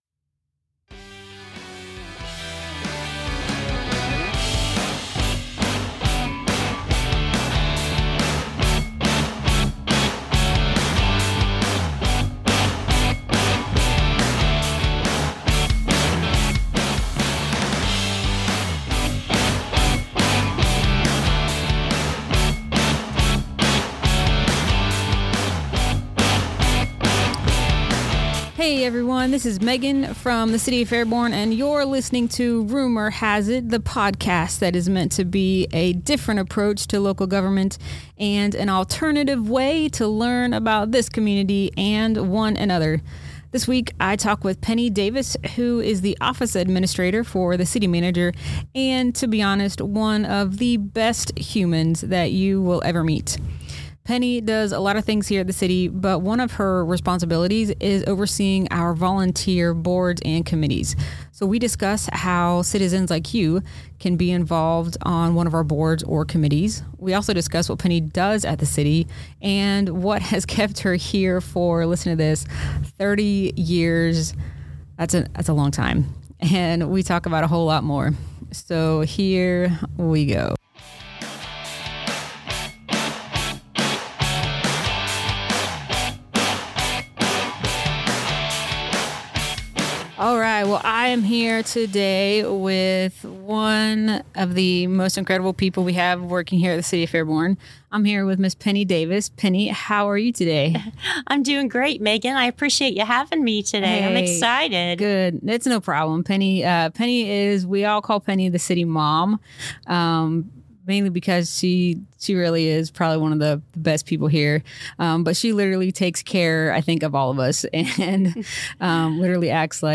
Love the Earth - Interview